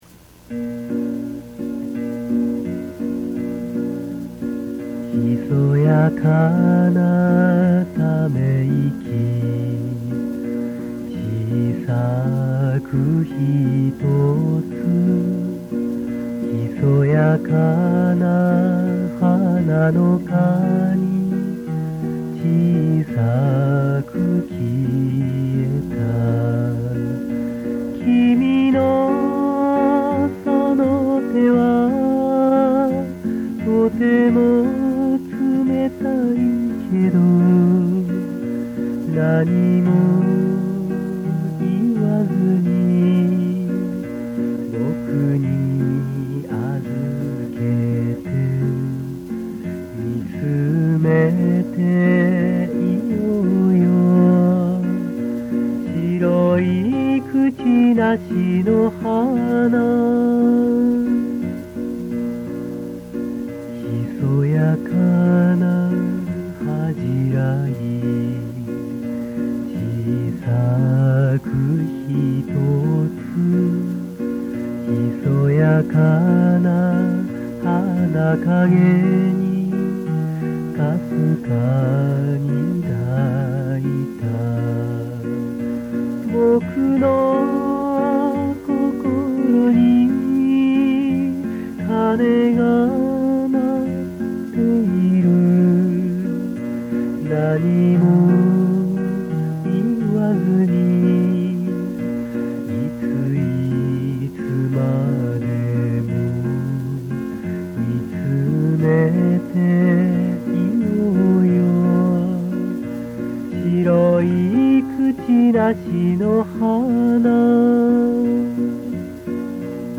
２００６．３．６アップ　　これも２０歳代後半の声です。